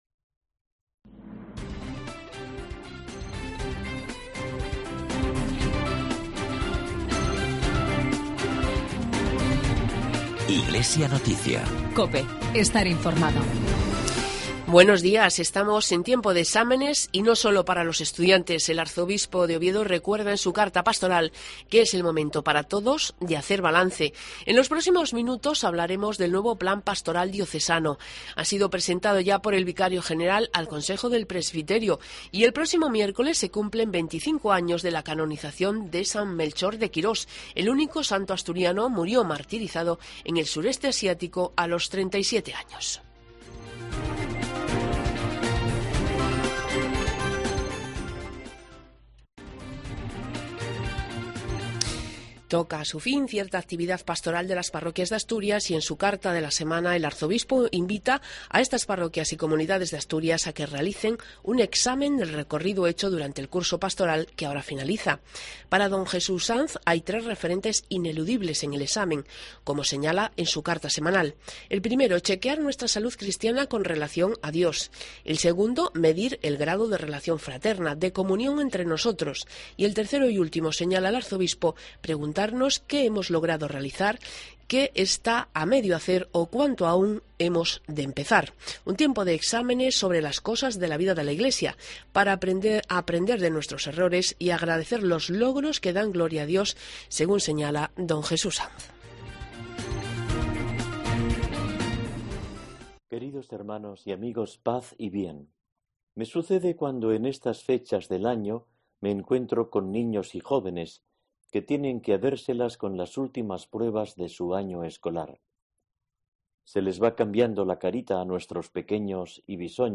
Programas de noticias de la Iglesia asturiana 16 de junio